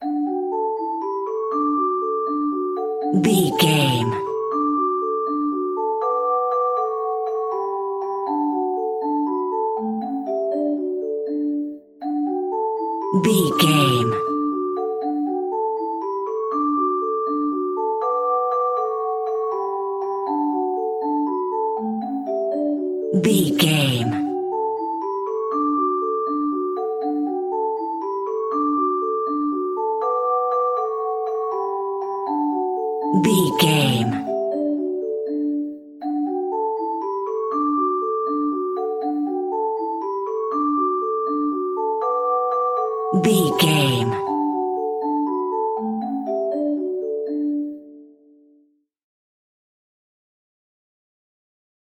Ionian/Major
D
Fast
nursery rhymes
childrens music